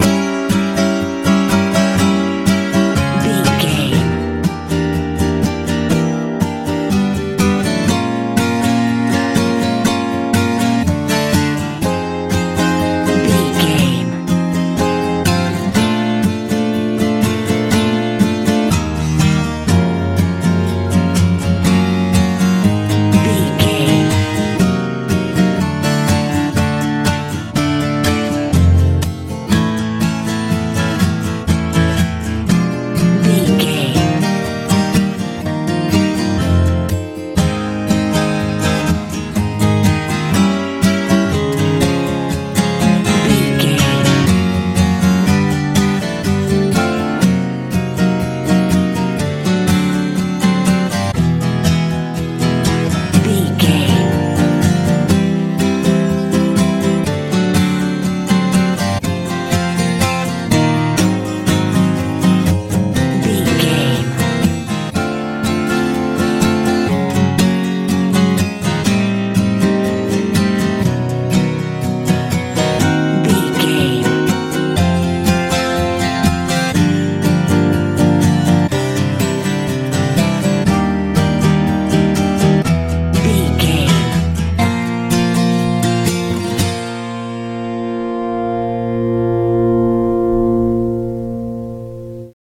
easy campfire
Locrian
light
acoustic guitar
tranquil
soft
relaxed
melancholy
calm